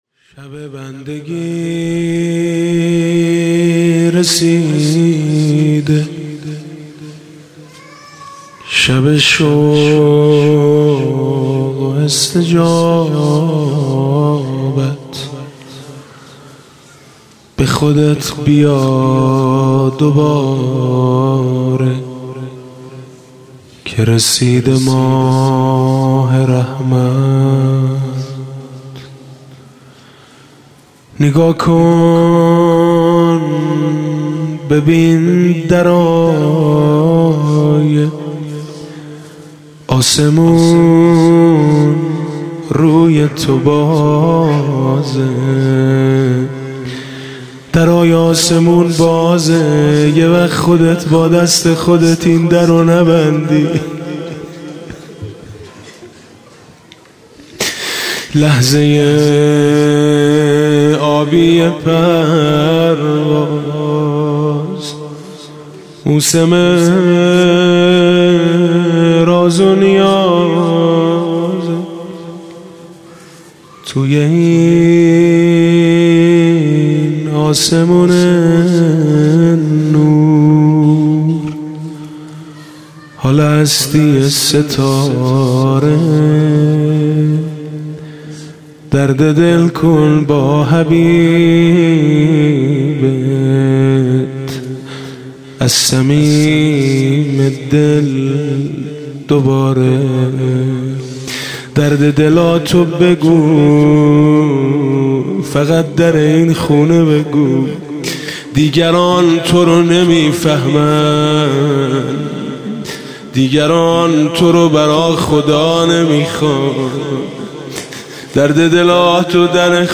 2 خرداد 97 - هیئت میثاق با شهدا - مناجات - درد دل کن با حبیبت